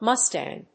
/mˈʌstæŋ(米国英語)/
mustang.mp3